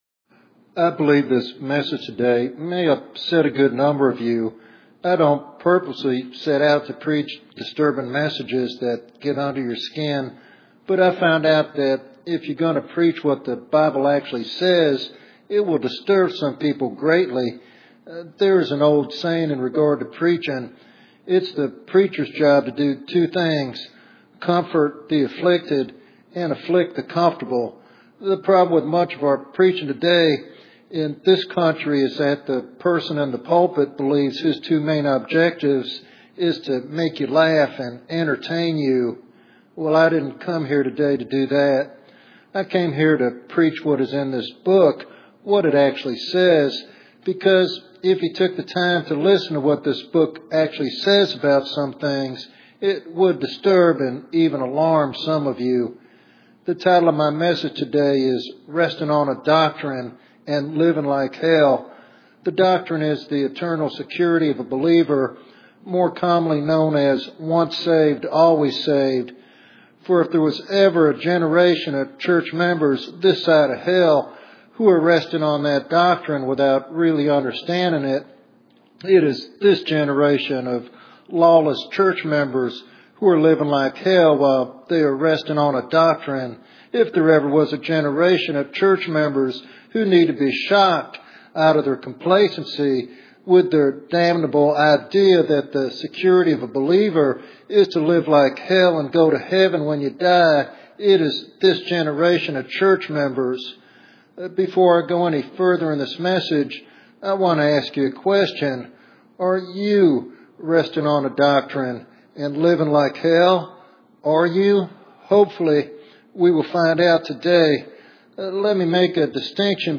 This message serves as a powerful reminder that genuine faith produces a transformed life marked by obedience and holiness.